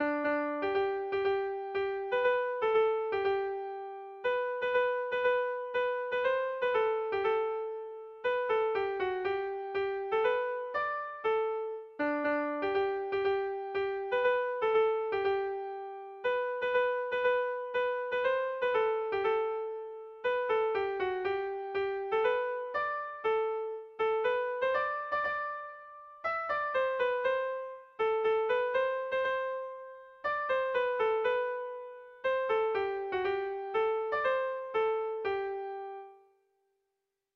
Bertso melodies - View details   To know more about this section
Kontakizunezkoa
ABDEF